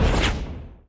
poly_shoot_missile.wav